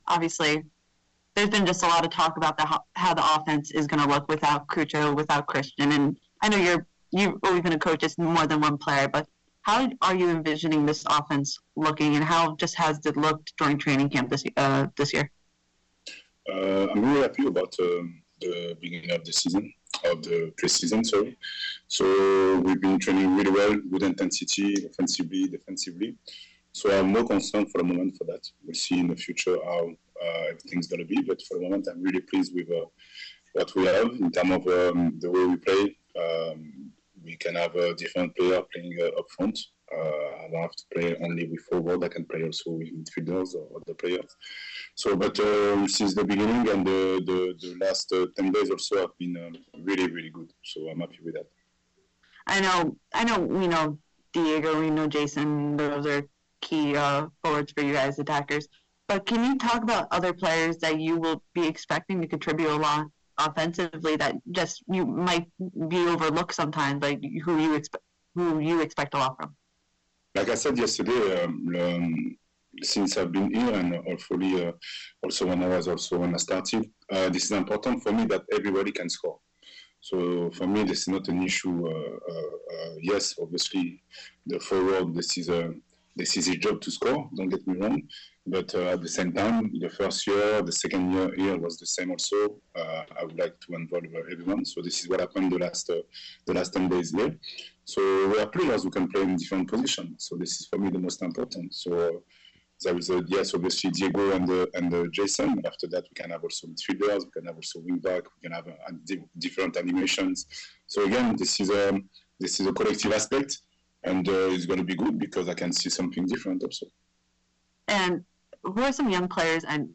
Wilfried Nancy Crew head coach Crew Training in Florida February 2025 Q & A on new look Crew